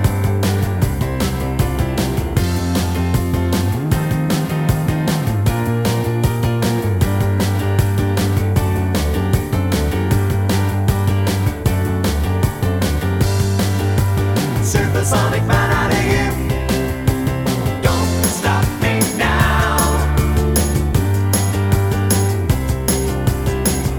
Short Ending Rock 3:18 Buy £1.50